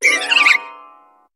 Cri de Roserade dans Pokémon HOME.